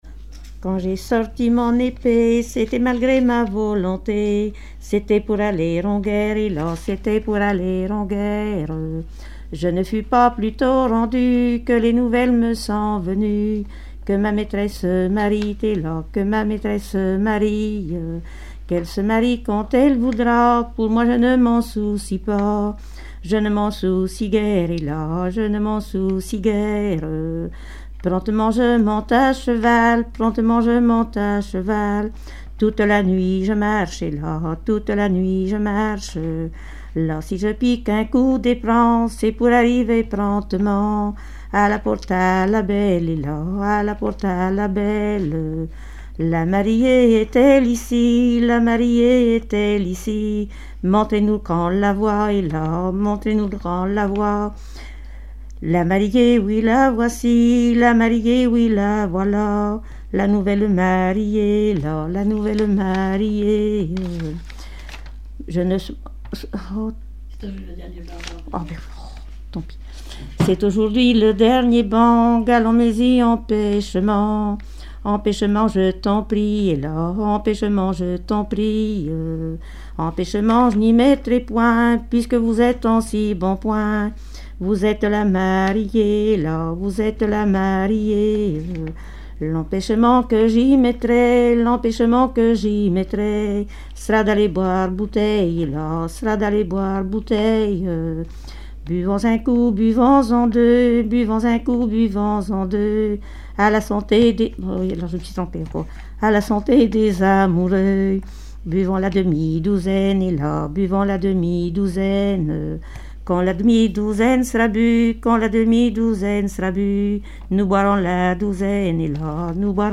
danse : ronde
chansons traditionnelles et populaires
Pièce musicale inédite